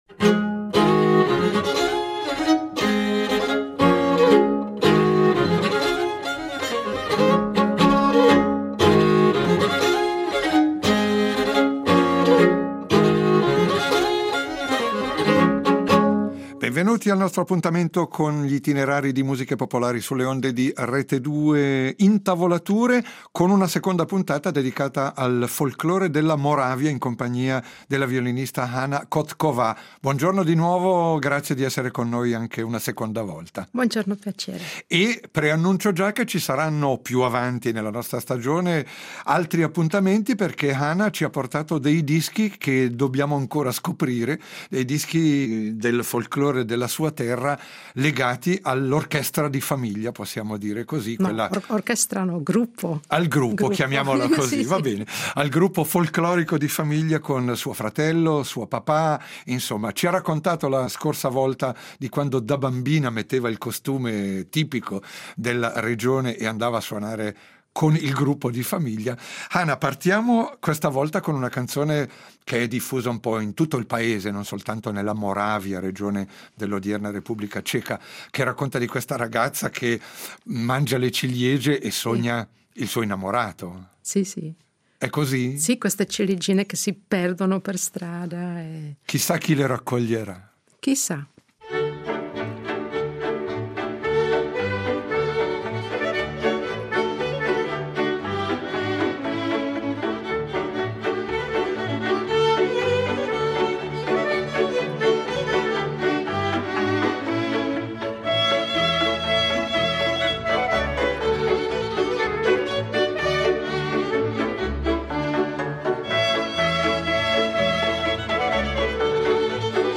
il folklore della Moravia
Lo facciamo a partire da una registrazione storica della Radio di Brno, che nel 1966 aveva invitato un ensemble tipico ad interpretare canti e danze della tradizione: violini, clarinetto, cimbalom e varie voci per un repertorio pieno di ritmo e di melodia, espressione di un mondo prevalentemente rurale, a volte con malinconia ma spesso con tanta gioia di vivere!